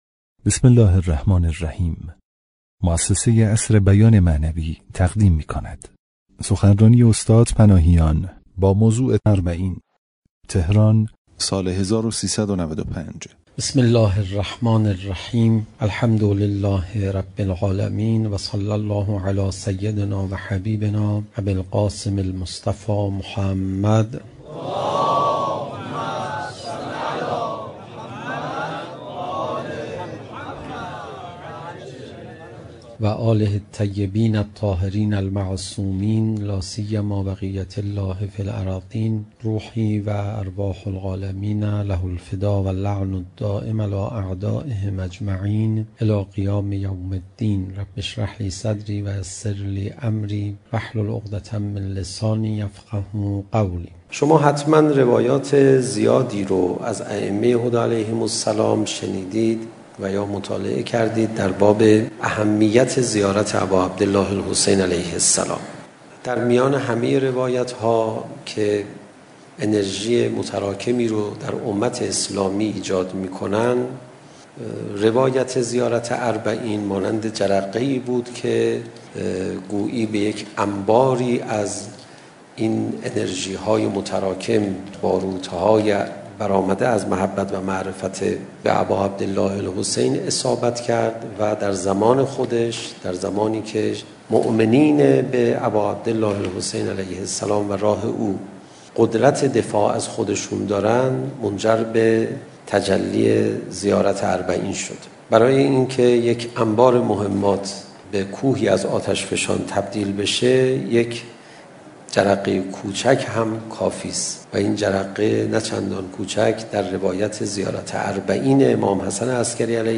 همایش ملی ظرفیت‌های تمدنی اربعین